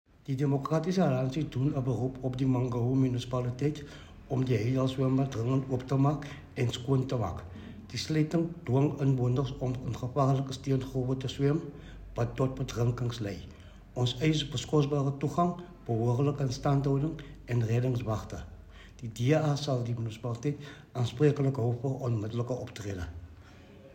Afrikaans soundbites by Cllr Attie Terblanche and